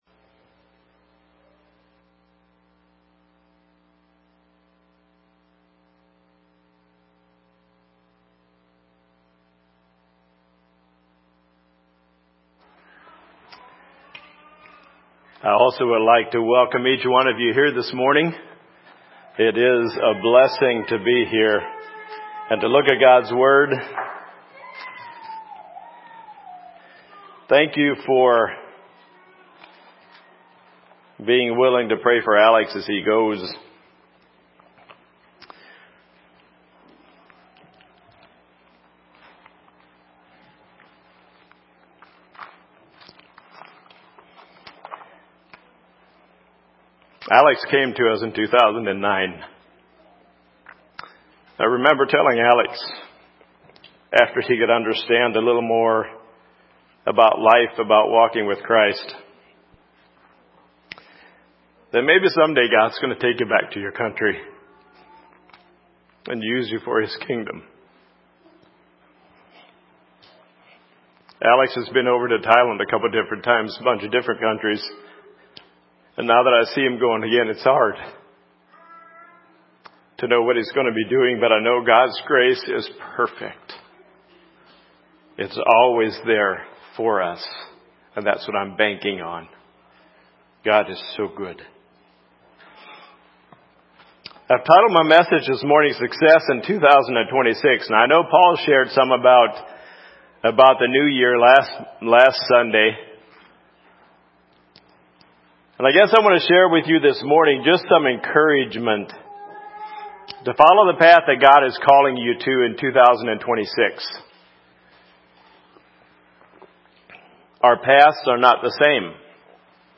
Sermons - Calvary Chapel